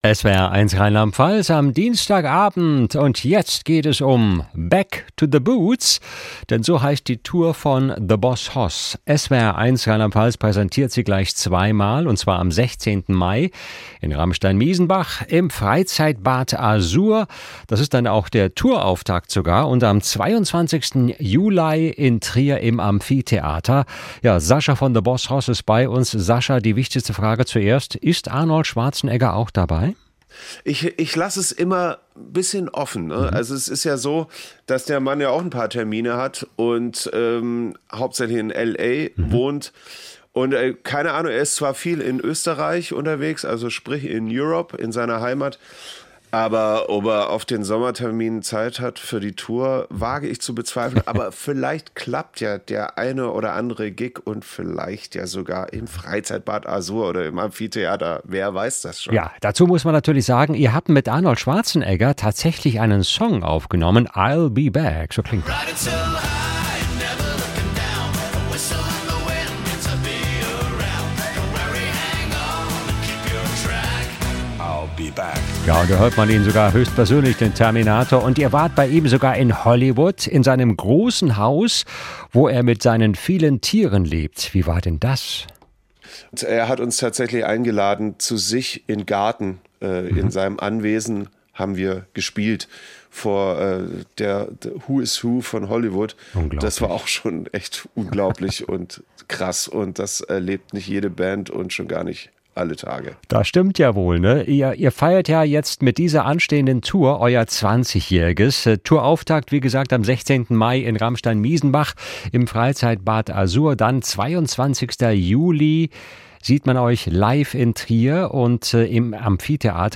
Interview mit
Sasha Vollmer, The BossHoss